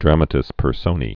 (drămə-tĭs pər-sōnē, drämə-tĭs pər-sōnī)